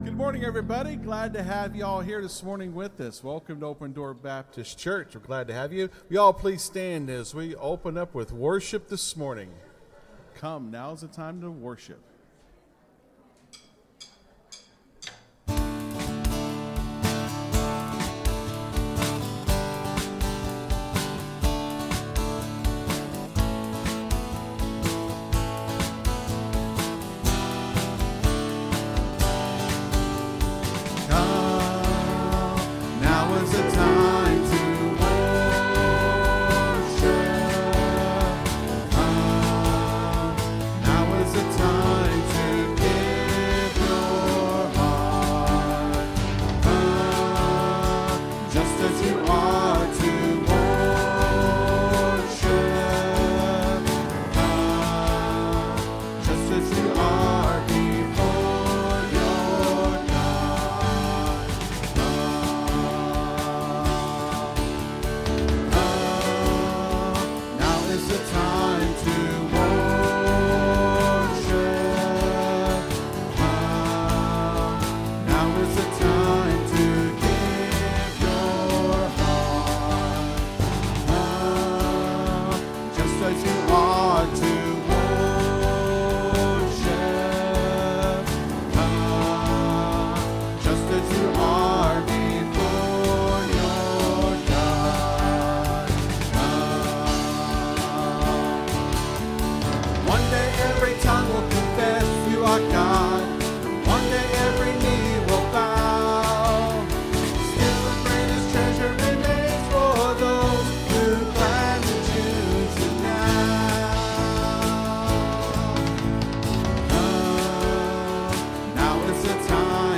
(Sermon starts at 22:45 in the recording).